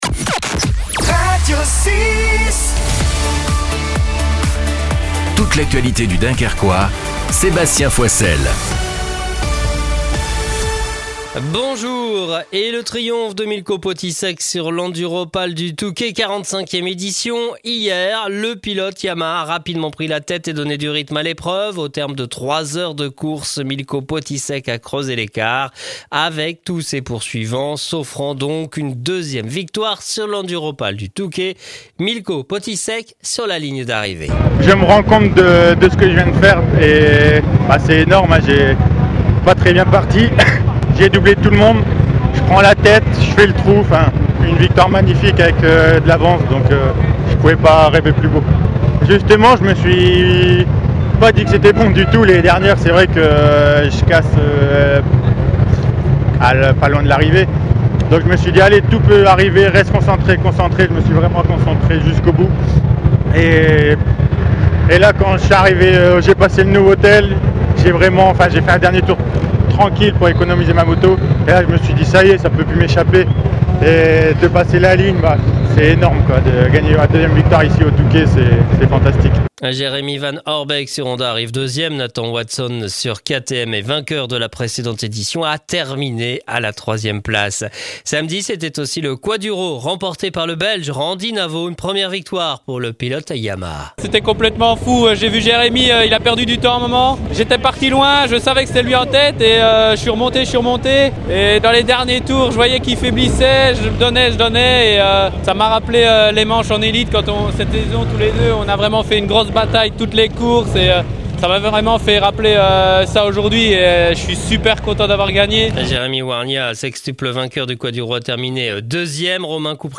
Le journal du lundi 3 février dans le Dunkerquois.